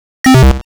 computerclose.WAV